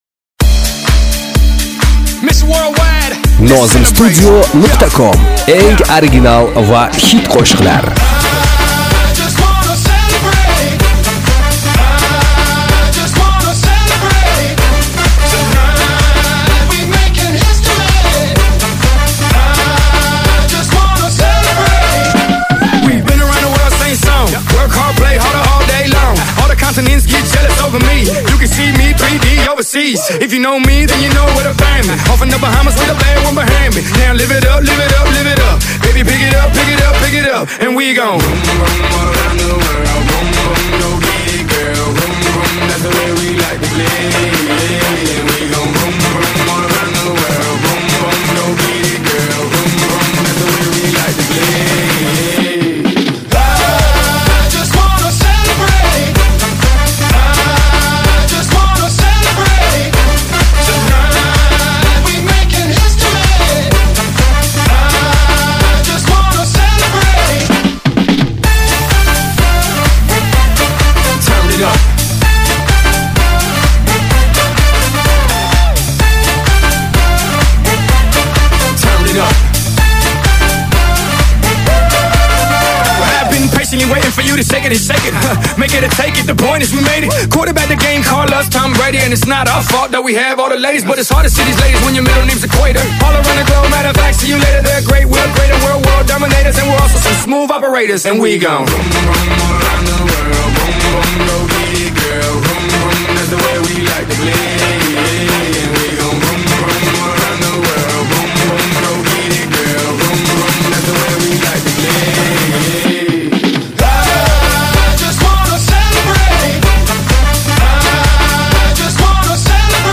MINUS MUSIC [792]